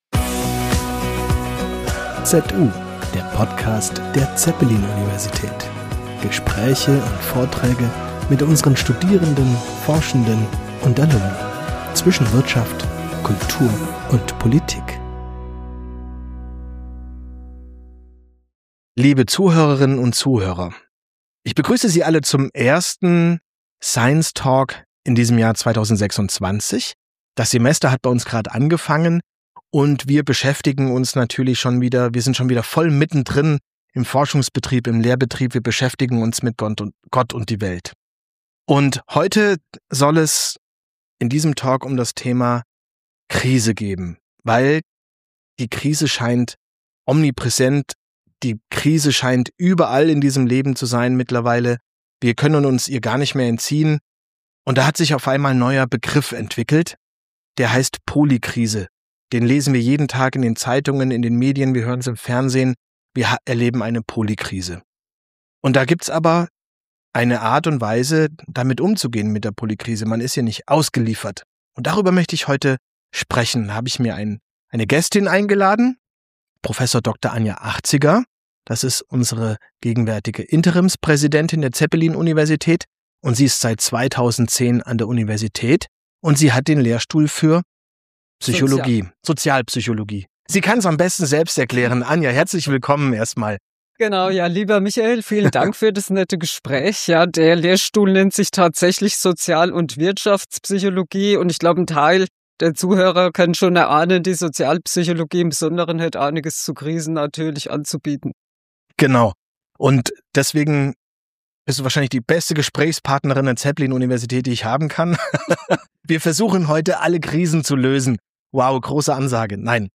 Polychance: Wo andere nur Krisen sehen, sehen wir das Potenzial | Science Talk